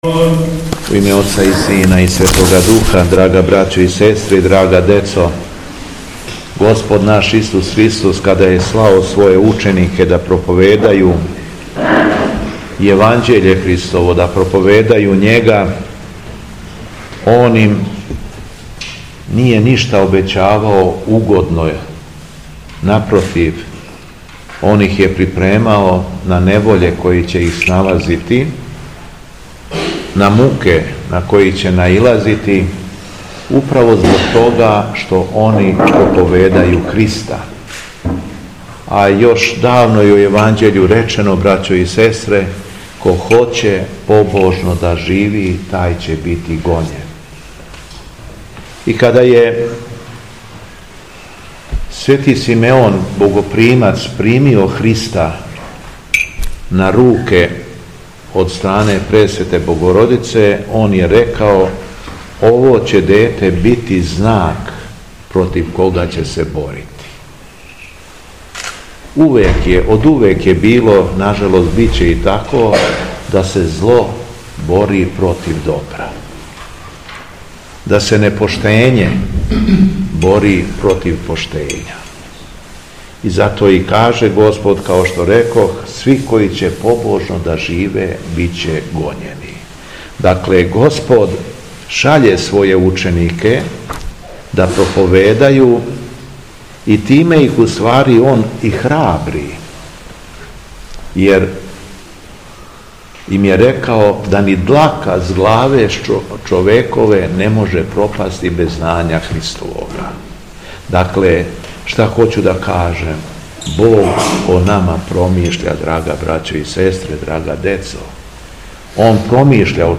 Беседа Његовог Преосвештенства Епископа шумадијског г. Јована
Након прочитаног Јеванђеља по Матеју Преосвећени Владика се обратио верном народу и ученицима: